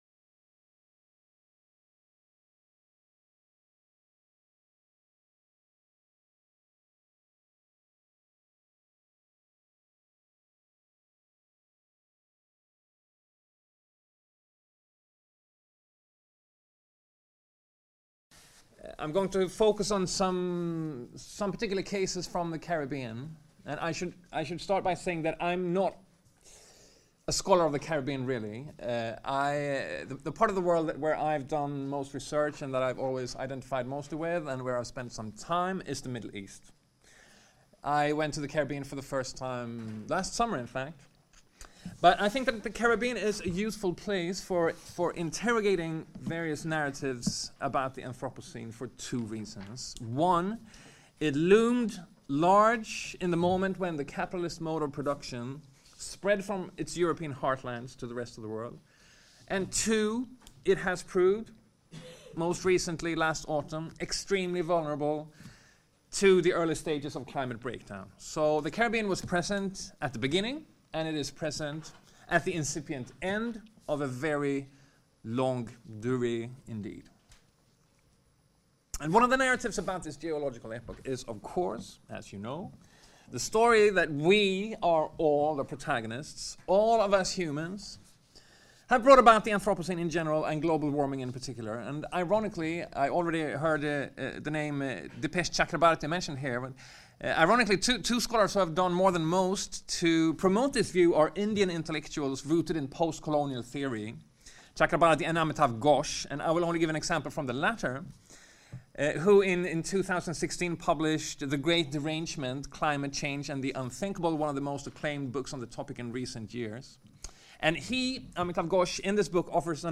Journée d’étude Mondes Caraïbes et Transatlantiques en Mouvement - Mardi 20 mars De l’anthropocène au plantationocène : racialisation et politiques de la nature Cette journée est consacrée à une tentative de penser les rapports de pouvoir opérant à l’échelle des interactions entre l’humain et le « Système-Terre » dans ce que les scientifiques désignent la « zone critique ».